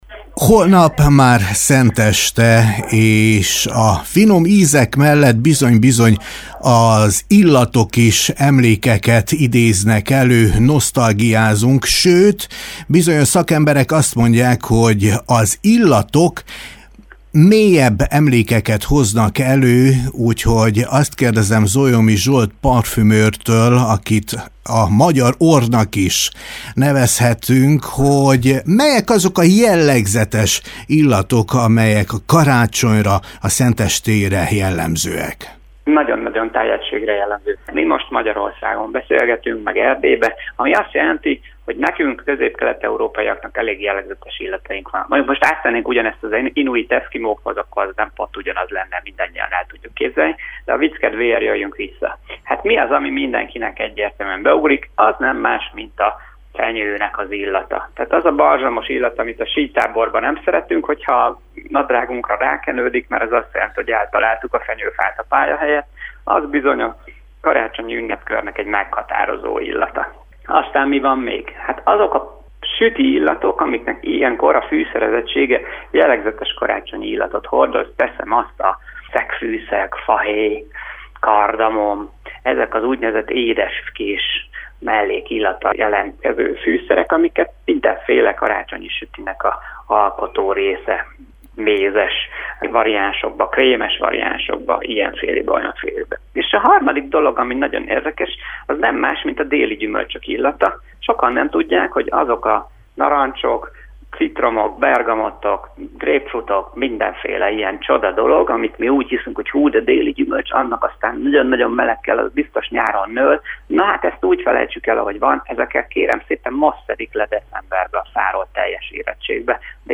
A következő beszélgetésből az is kiderül, hogy milyen illatot visel a parfümkreátor az ünnepen, illetve, hogy milyen illatemlékei vannak gyerekkorából.